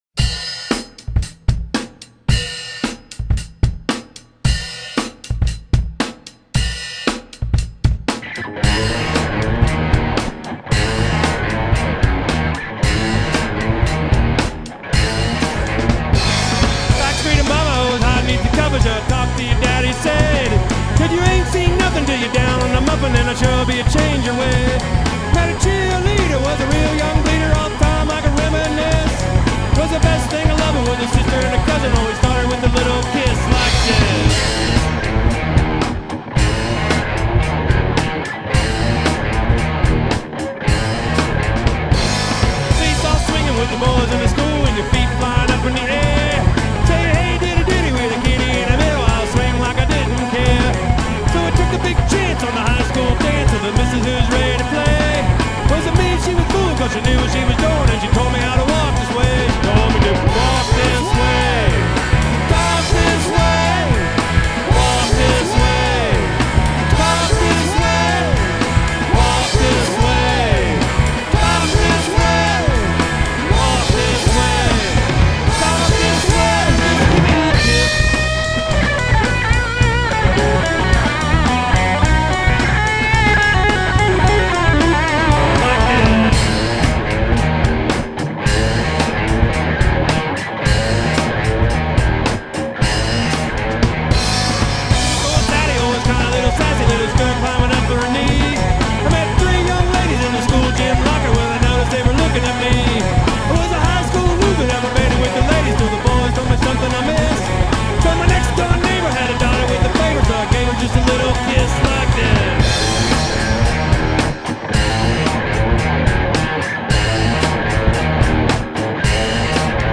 Drums and Backing Vocals
Bass and Vocals